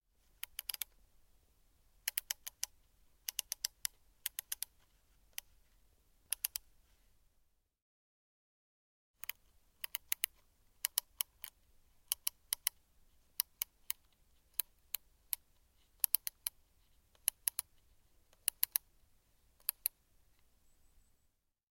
Тихий звук наматывания шнура на катушку